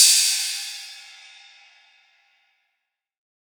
Open Hats
MURDA_HAT_OPEN_URUS.wav